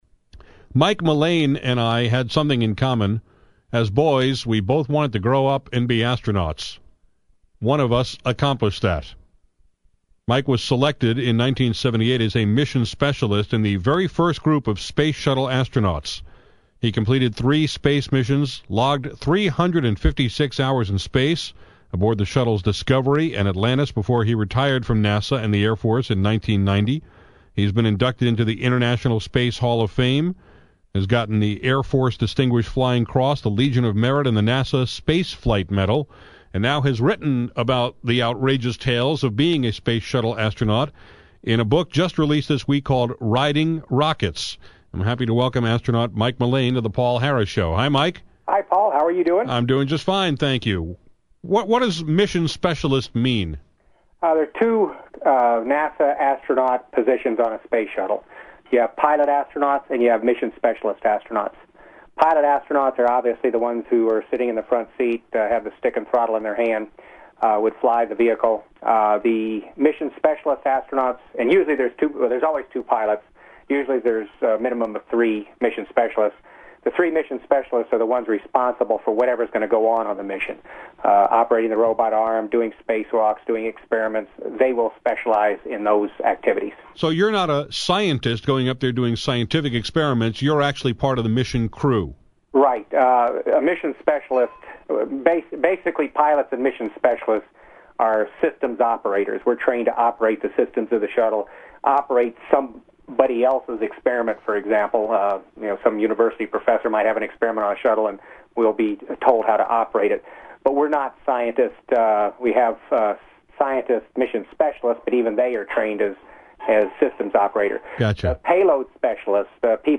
With the 20th anniversary of the space shuttle Challenger explosion next week, I talked with astronaut Mike Mullane on my show this afternoon.